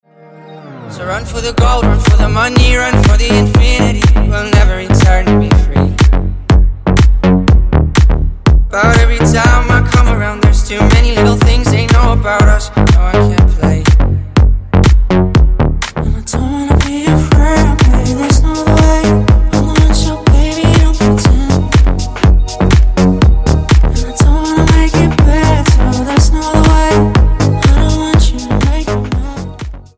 ритмичные
мелодичные
dance
спокойные
club
качающие
Bass
мужской и женский вокал
Фрагмент чувственного трека